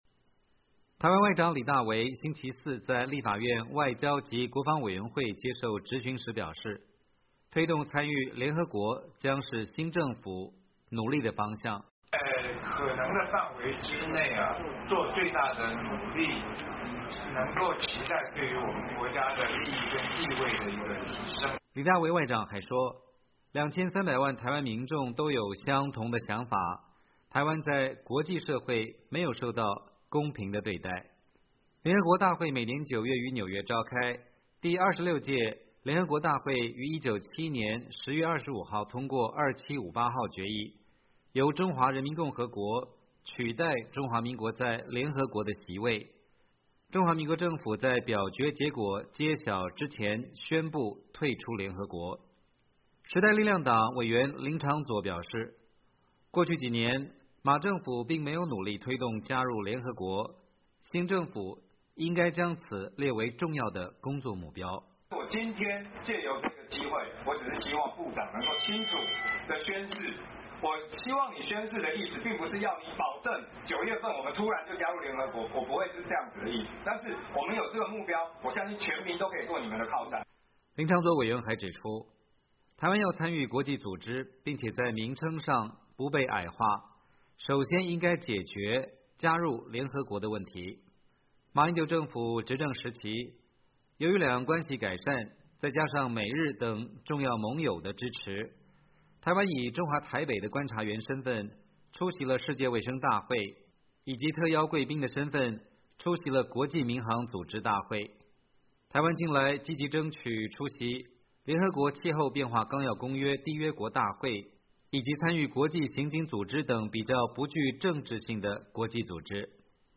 台湾立法院外交及国防委员会6月2号质询的情形
台湾外长李大维星期四在立法院外交及国防委员会接受质询时表示，推动参与联合国将是新政府努力的方向。